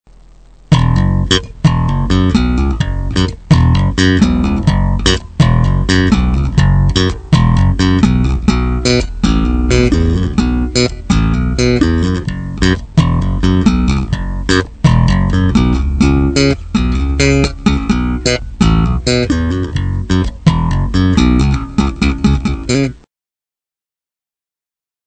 09 - Bass - Groove Nr.06